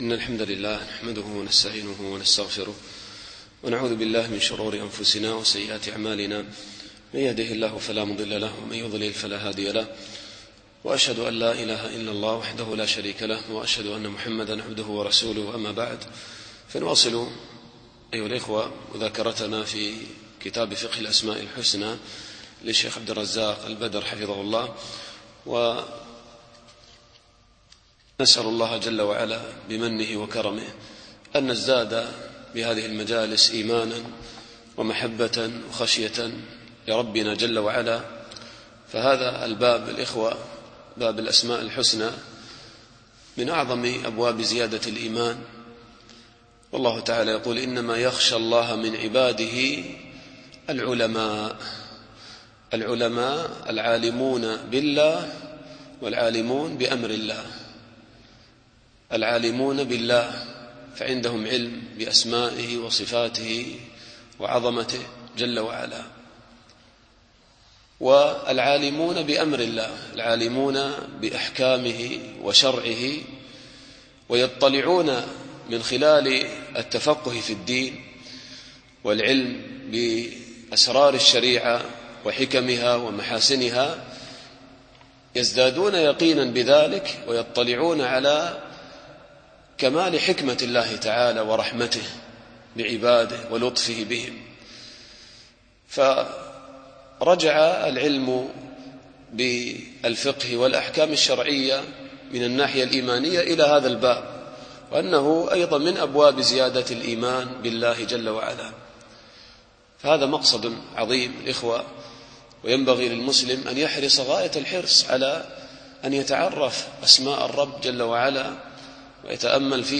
شرح كتاب فقه الأسماء الحسنى للشيخ عبدالرزاق البدر